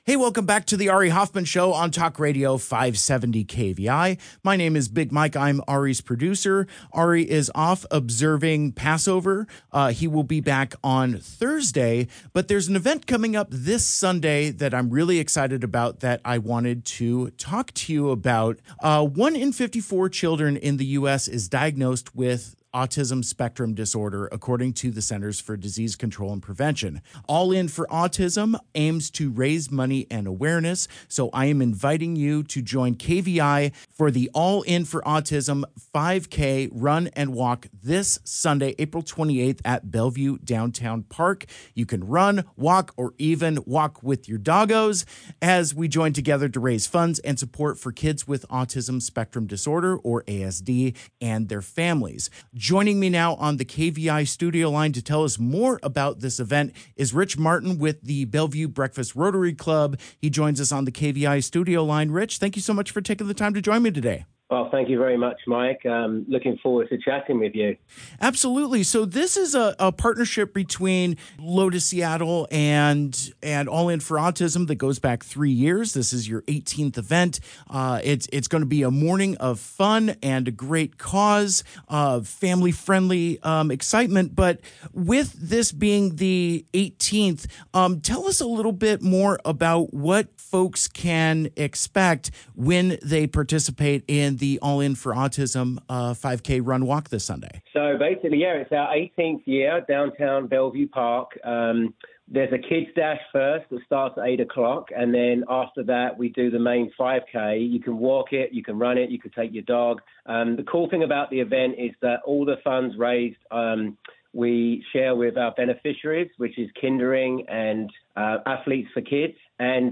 INTERVIEW: Learn all about All in for Autism 5K Run Walk — This Weekend!